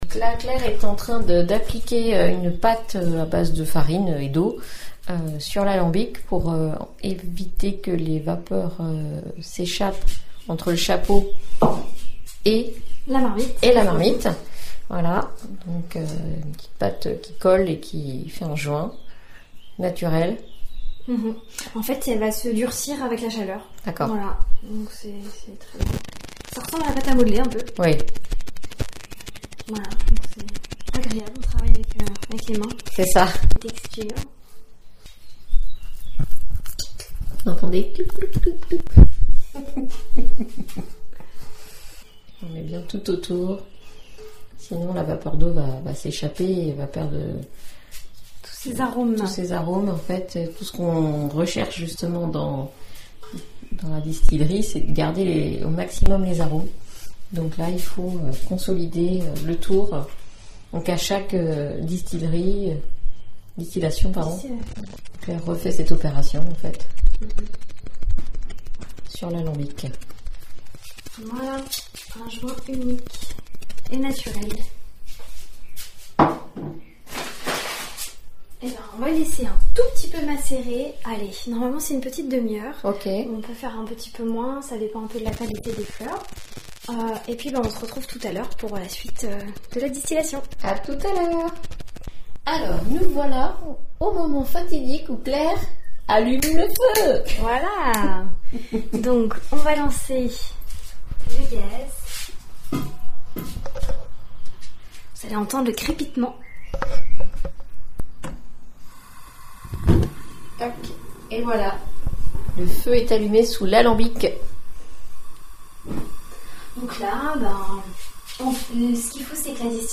reportage au Piton du Dehors à Mont-Saint-Jean